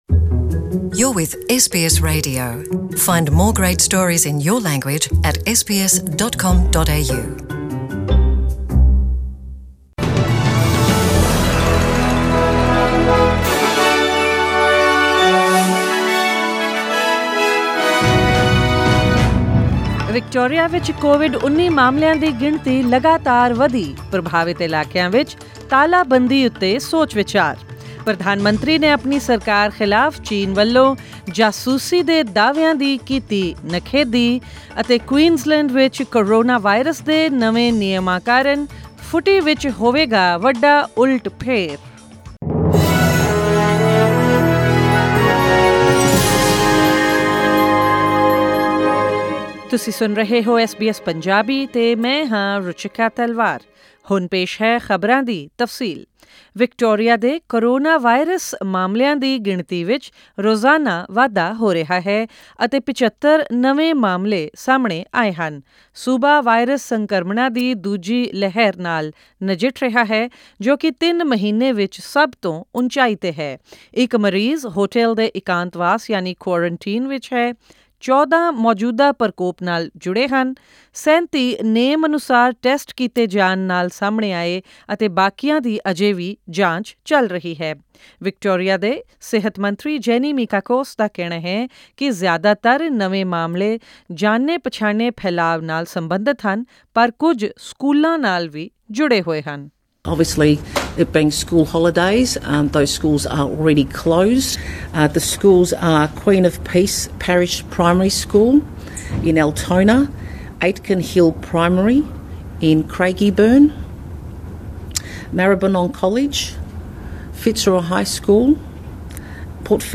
To hear the full news bulletin, click on the audio player above.